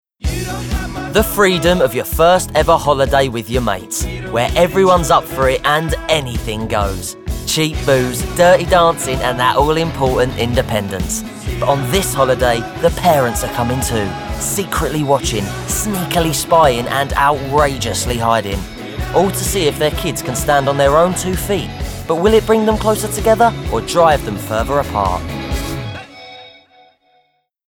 20/30's London/Neutral,
Natural/Youthful/Upbeat